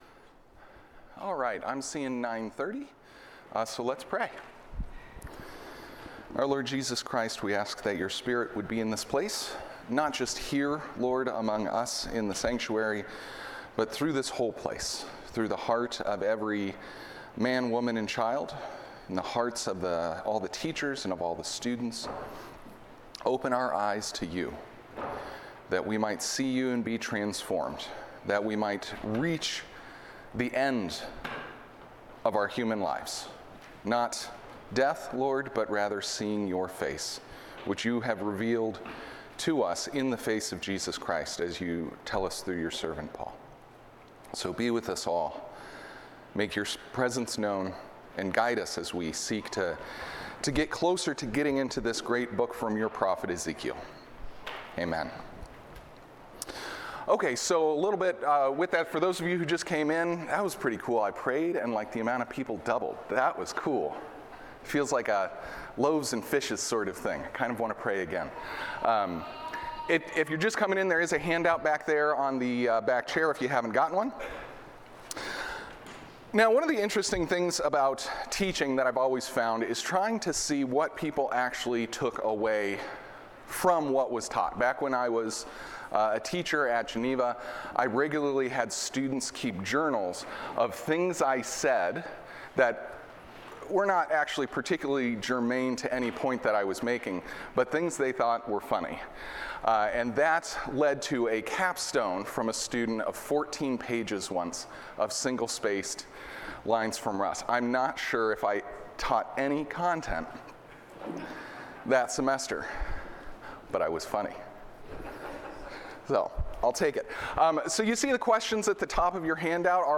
The Call of the Prophet Series: Ezekiel Sunday School